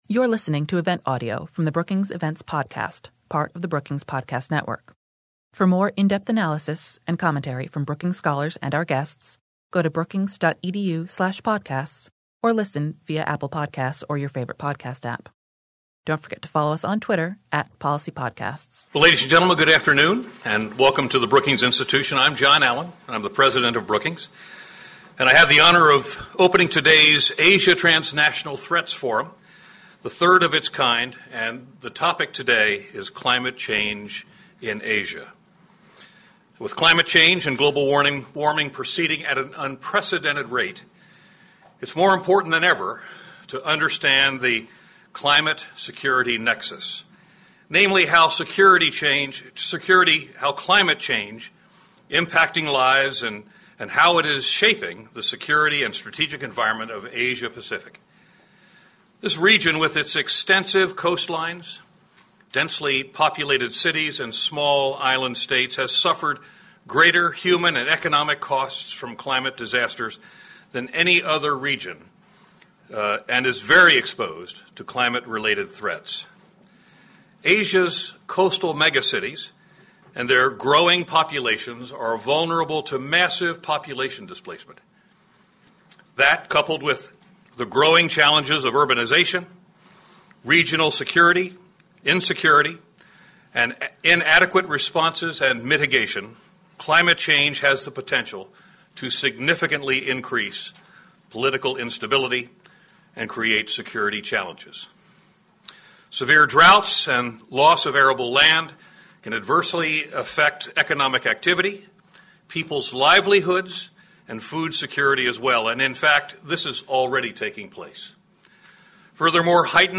Welcome remarks and keynote